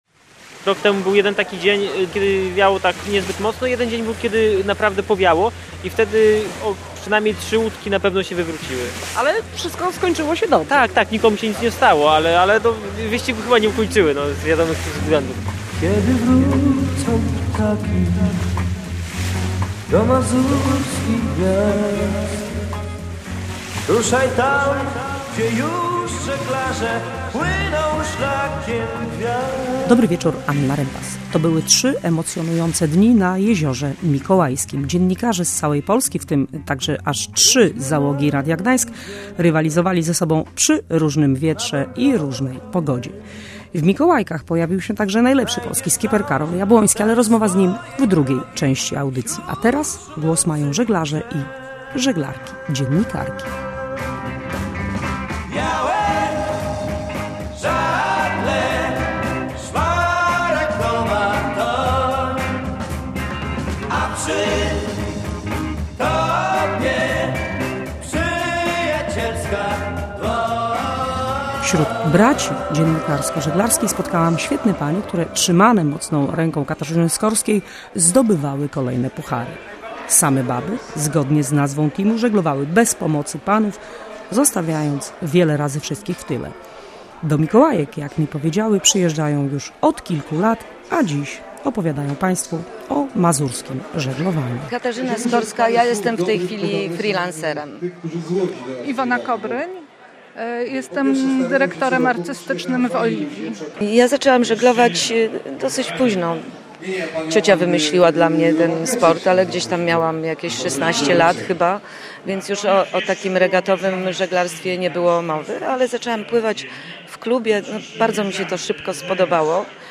W książce snuje opowieść o swoim życiu, marzeniach, tęsknocie za ojczyzną i o podróży, która ciągle trwa. A w tle audycji Otwarte Żeglarskie Mistrzostwa Polski Dziennikarzy w Mikołajkach.